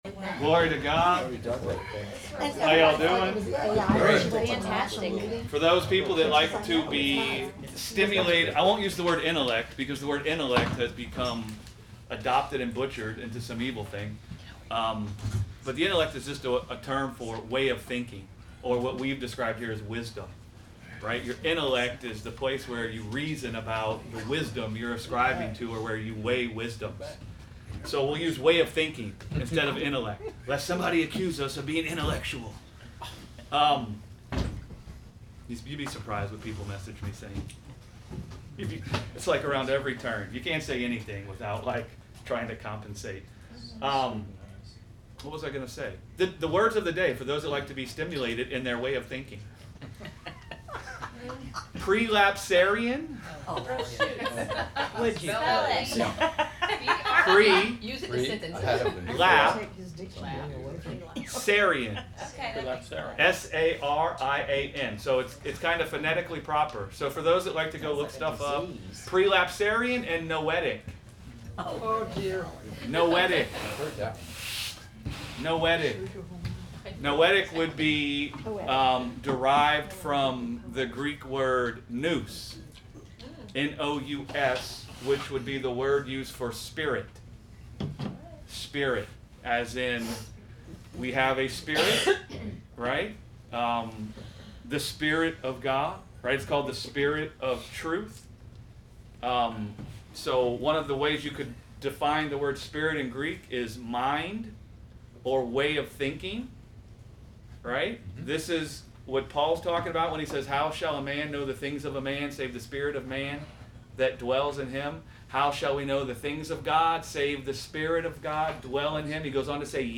Sunday Bible Study: Taste and See That the Lord is Good - Gospel Revolution Church